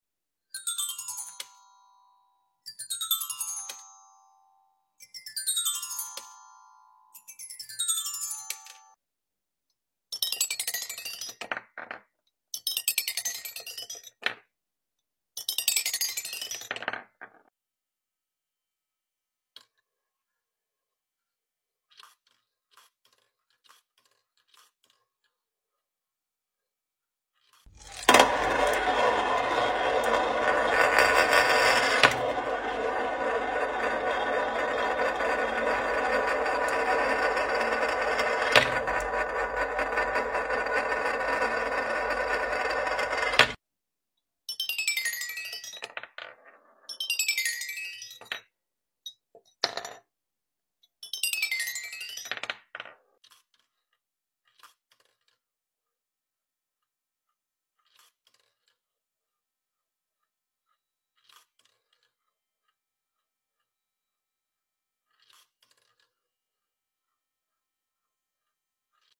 Toy Cars Play The Xylophone Sound Effects Free Download